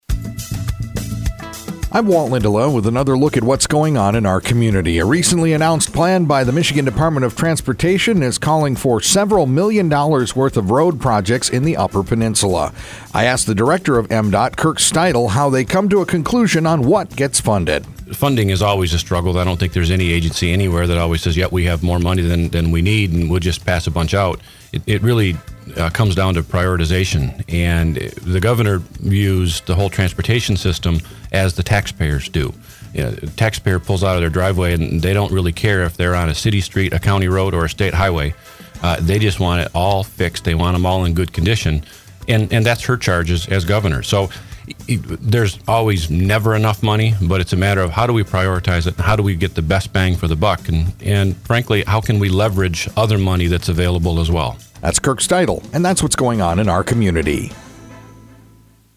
INTERVIEW: Kirk Steudle, Director, M-DOT